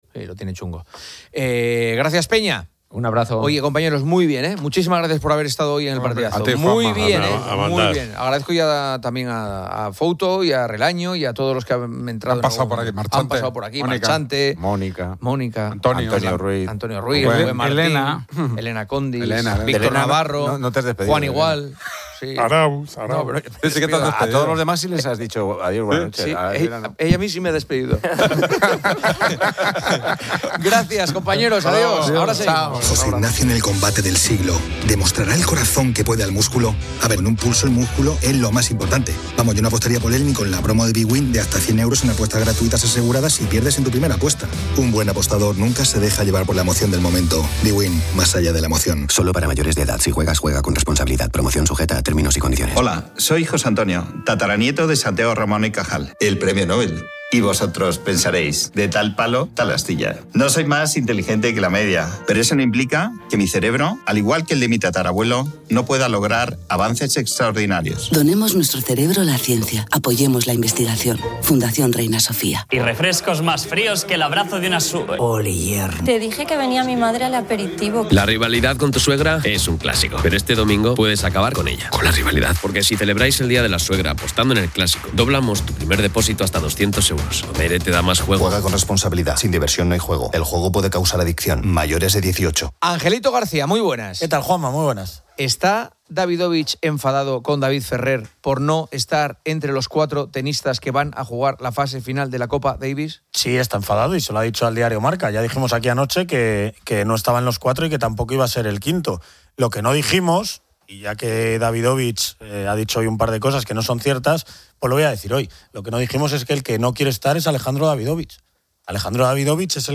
Un segmento de radio saluda a los oyentes y presenta a Wina Max, que promociona apuestas deportivas y juego responsable. Carlos Moreno, "El Pulpo", en "Poniendo las calles", aborda el tema de los "bulos" y la desinformación. El humorista Oscar Terol ofrece una perspectiva cómica.